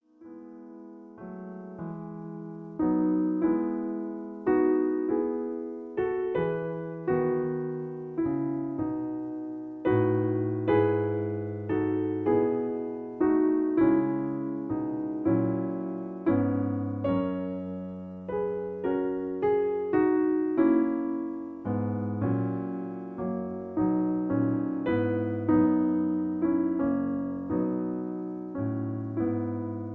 In E. Piano Accompaniment